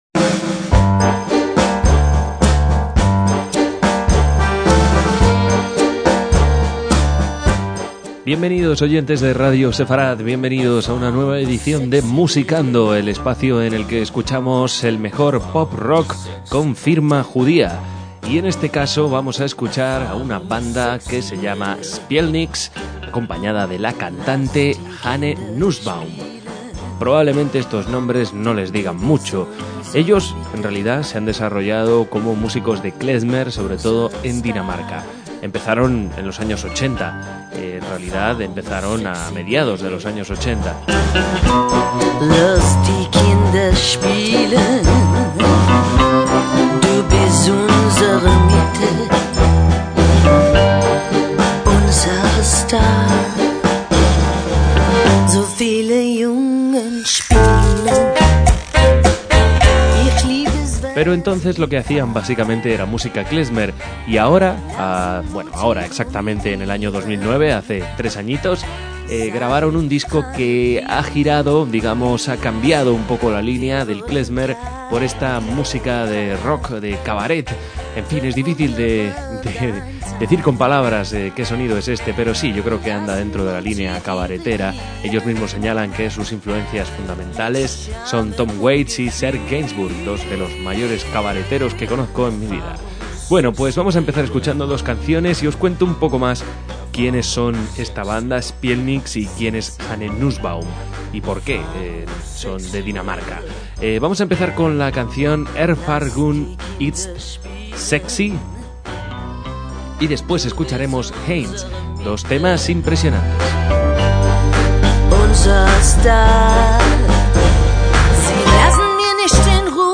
armónica, guitarra, mandolina
bajo
clarinete y saxos
batería